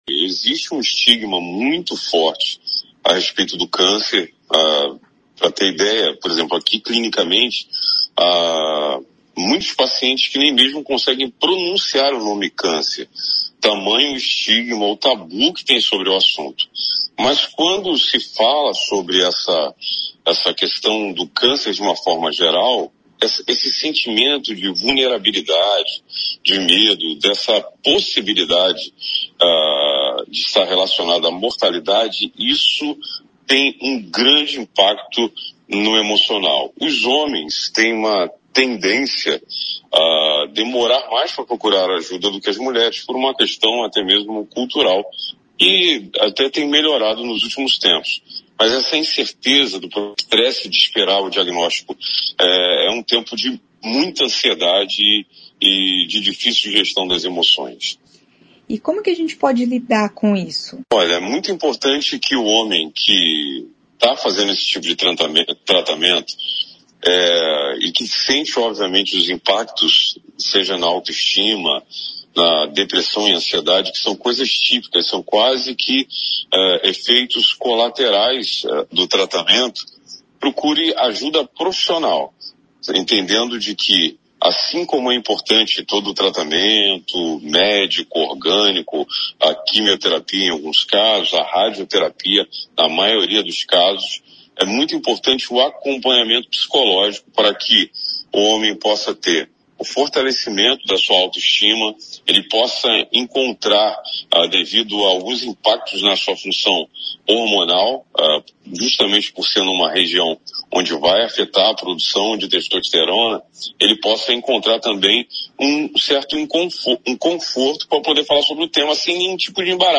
Série de Entrevistas